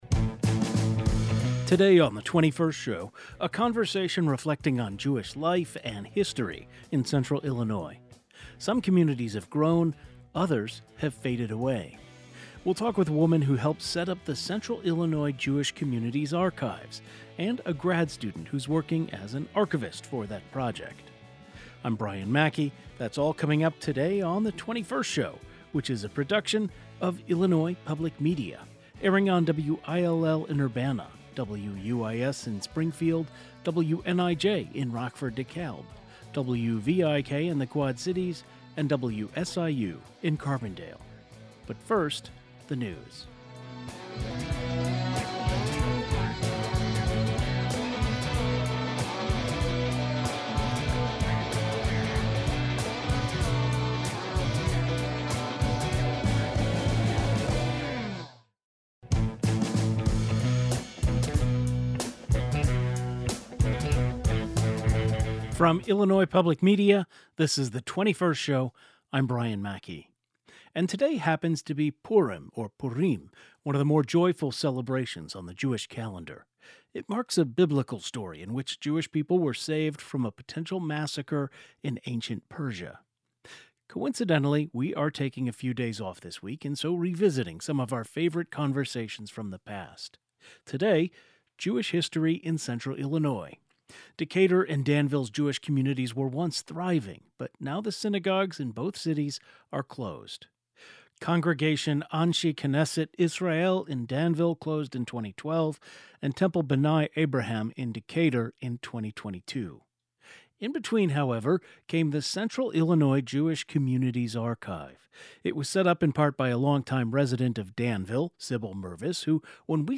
We spoke with an archivist for an oral history project and a longtime member of Danville's Jewish community.
Today's show included a rebroadcast of the following "best of" segment first aired October 8, 2025: Challenges and triumphs of Jewish communities in central Illinois.